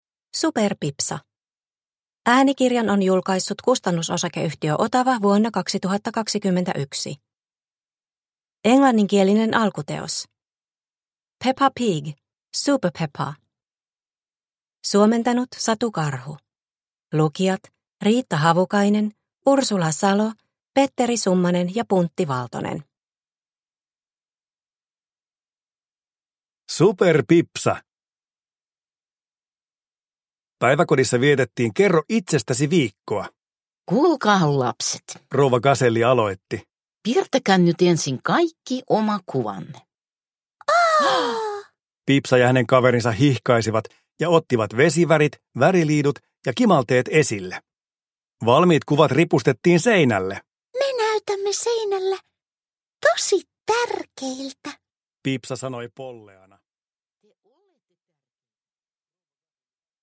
Pipsa Possu - Superpipsa – Ljudbok – Laddas ner